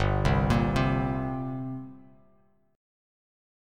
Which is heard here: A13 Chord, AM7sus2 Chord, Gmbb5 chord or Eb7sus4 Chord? Gmbb5 chord